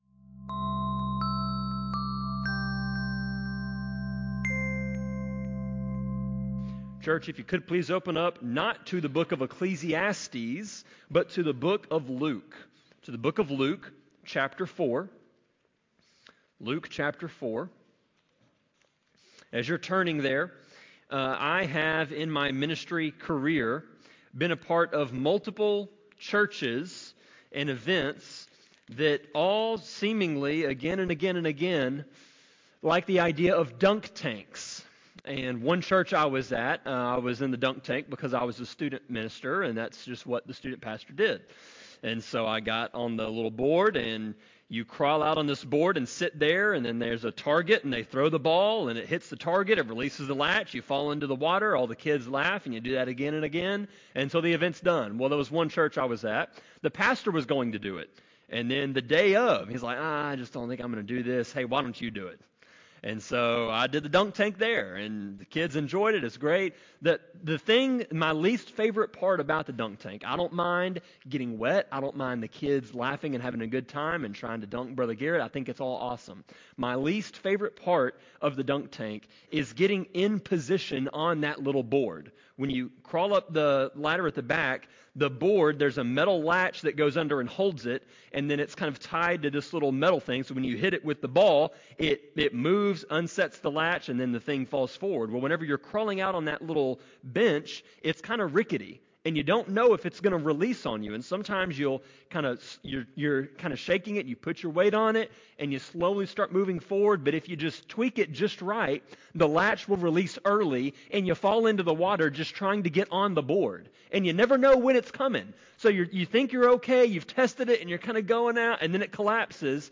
Sermon-25.4.13-CD.mp3